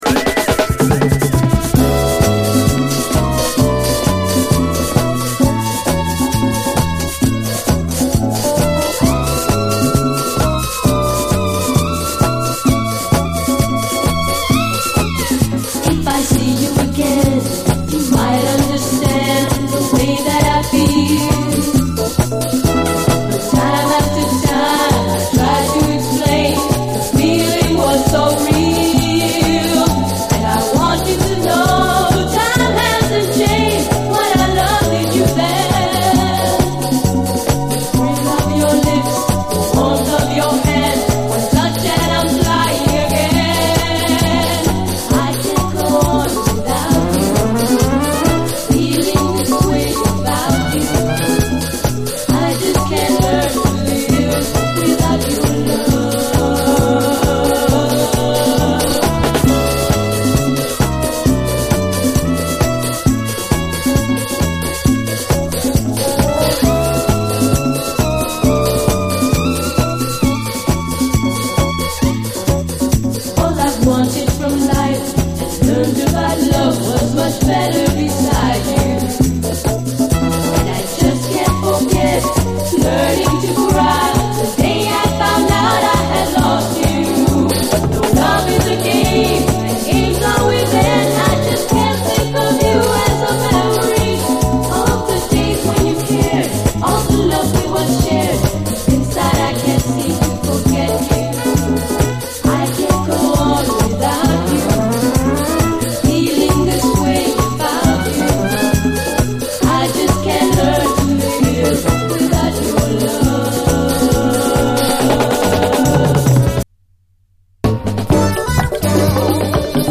SOUL, 70's～ SOUL, DISCO, LATIN